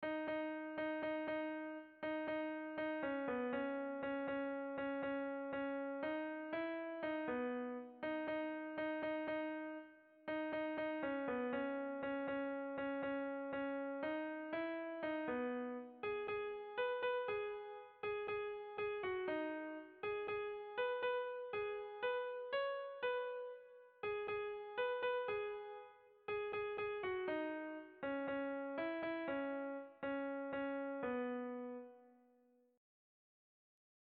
Bertso melodies - View details   To know more about this section
Irrizkoa
Bizkaia < Basque Country
Zortziko handia (hg) / Lau puntuko handia (ip)
AAB1B2